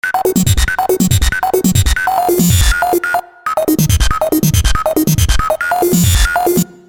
神秘的合成器
描述：我在fl studio中使用3x振荡器制作的奇怪的合成器
Tag: 140 bpm Dubstep Loops Synth Loops 1.16 MB wav Key : Unknown